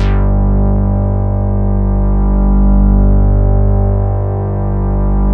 004_Mighty Moog G2.wav